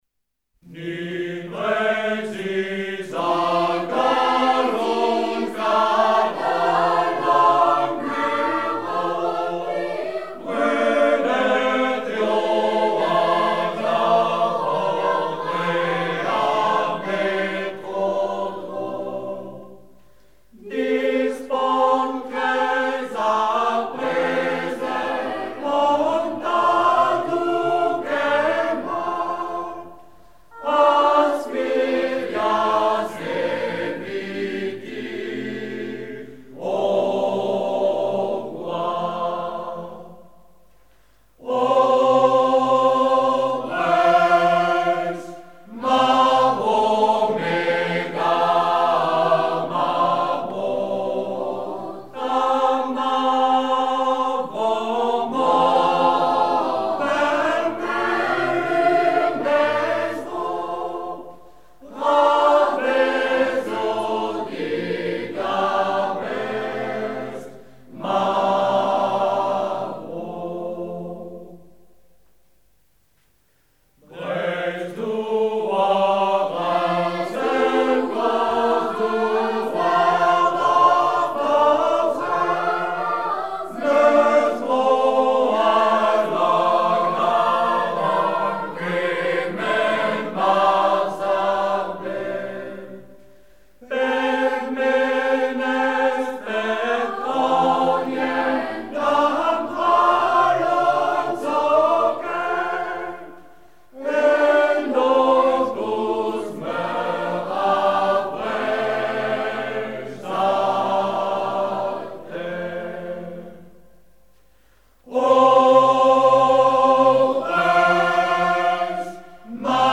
Hymne national